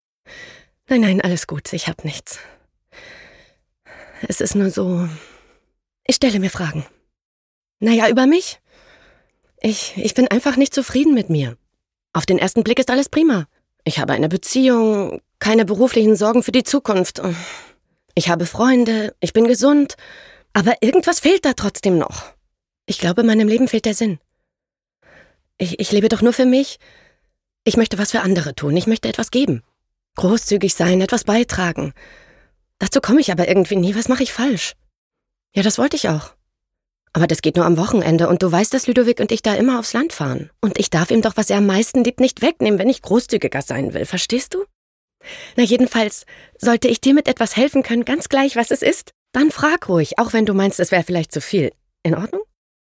sehr variabel, dunkel, sonor, souverän
Mittel minus (25-45)
Berlinerisch, Norddeutsch, Sächsisch
Synchron Demo (suchend, zugeknöpft)
Lip-Sync (Synchron)